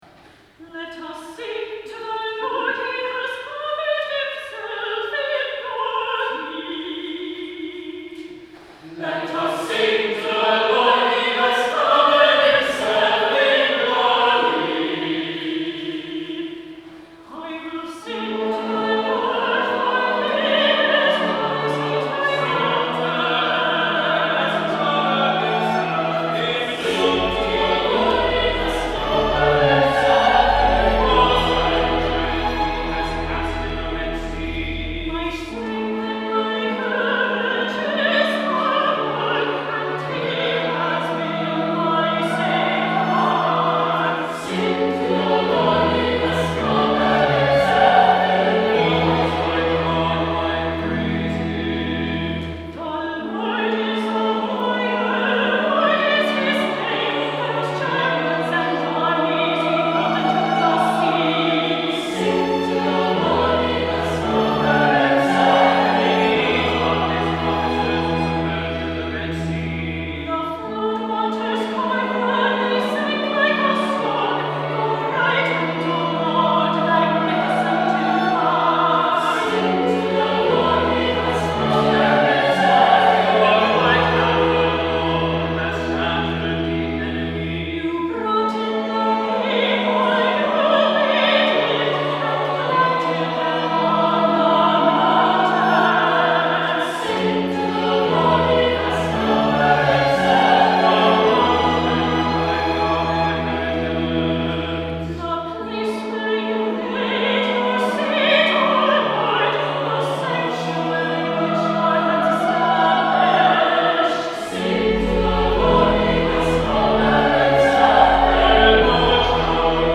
Lakewood Cluster Choirs of Saint Clement, Saint James and Saint Luke Sang this Song
2023 Easter Vigil